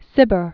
(sĭbər), Colley 1671-1757.